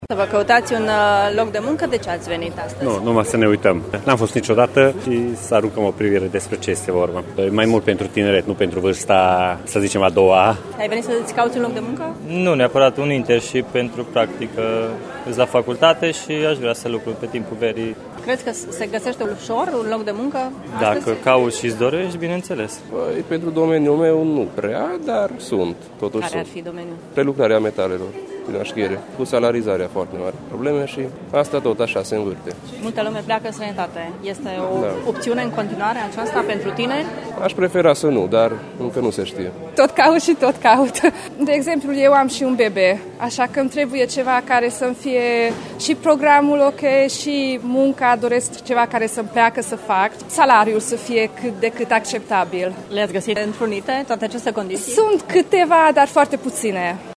Târgumureșenii au luat cu asalt Târgul de Cariere de la Târgu-Mureș, iar unii dintre ei au venit doar din curiozitate: